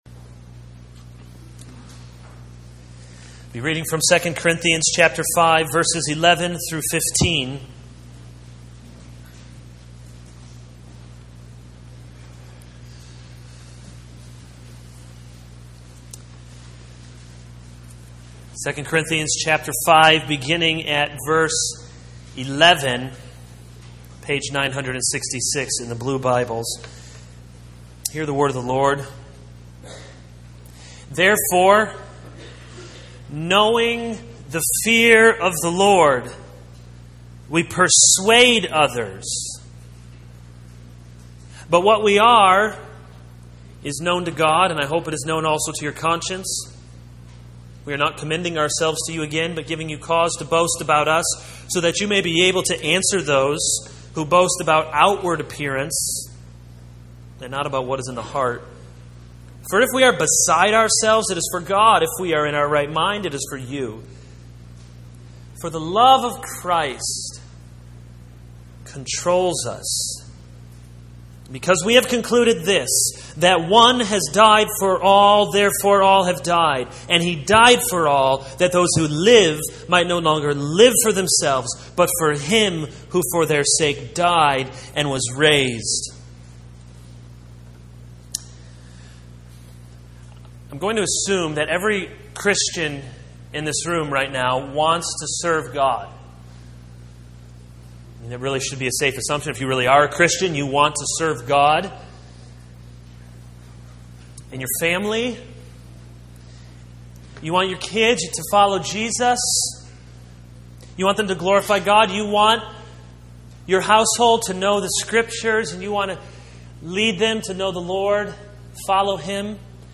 This is a sermon on 2 Corinthians 5:11-15.